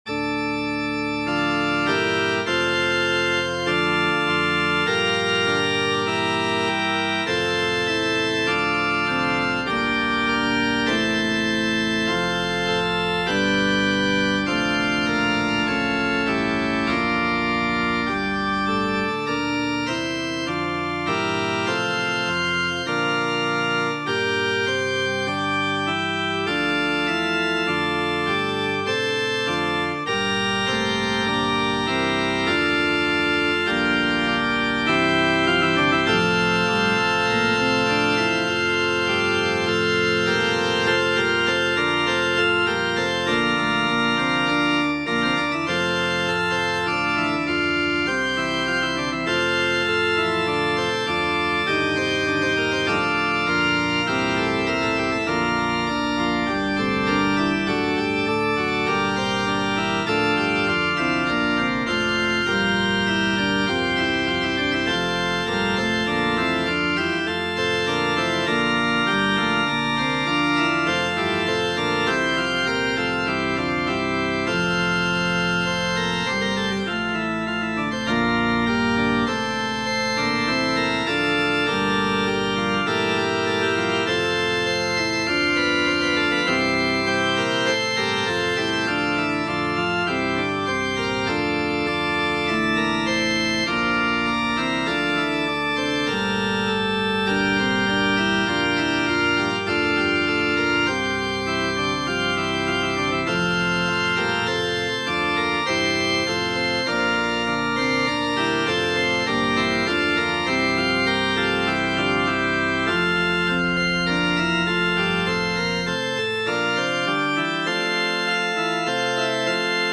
Muziko:
arangxis por komputilo